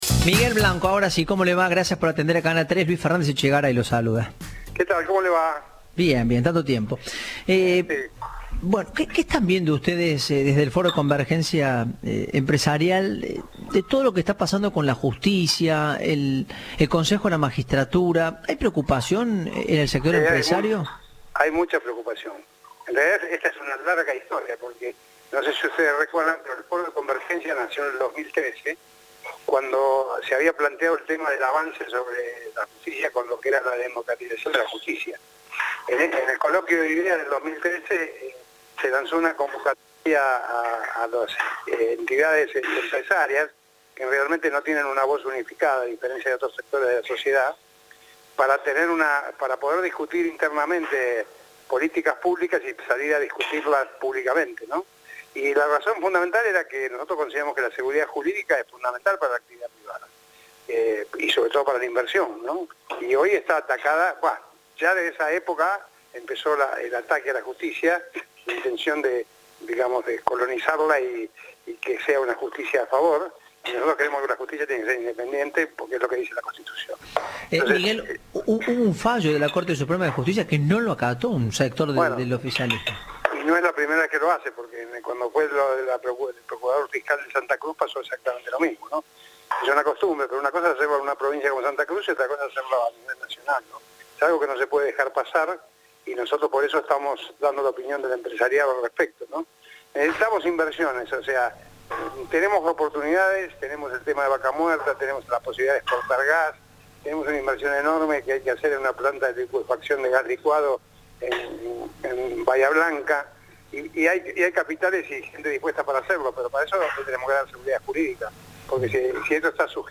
Entrevista "Informados, al regreso".